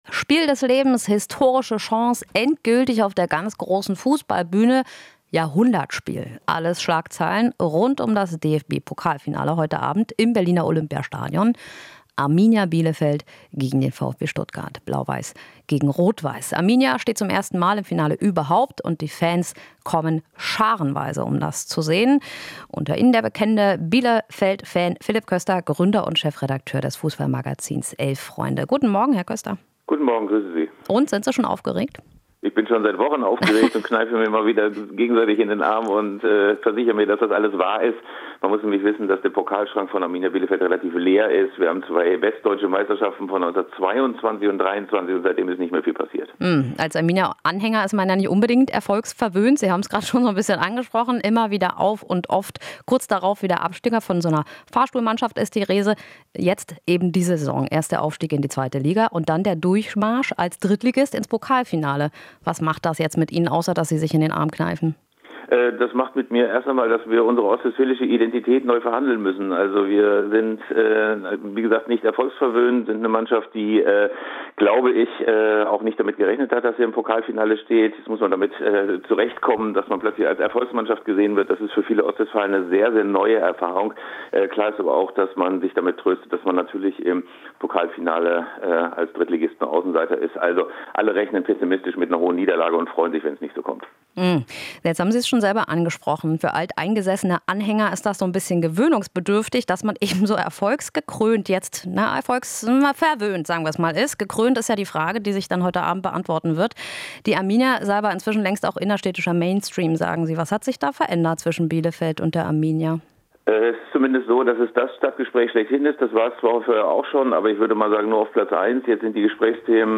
Interview - Sportjournalist: "Ganz Bielefeld wird heute im Zeichen des Pokalfinales stehen"